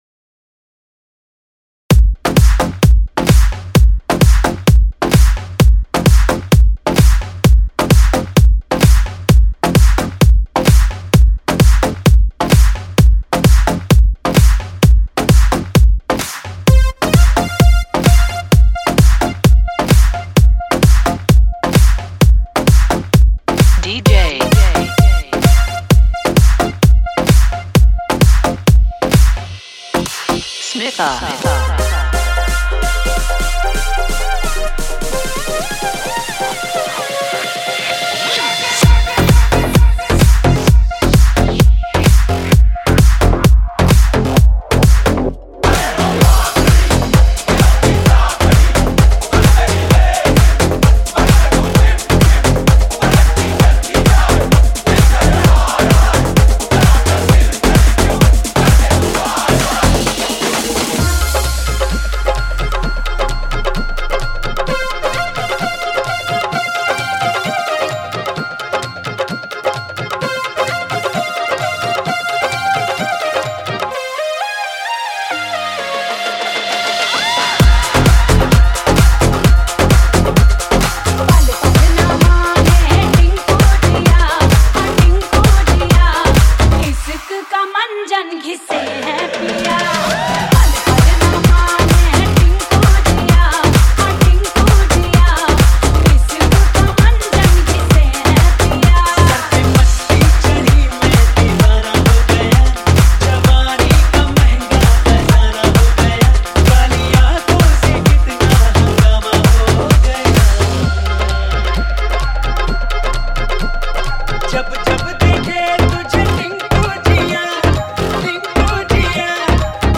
Various Artist Dj Remix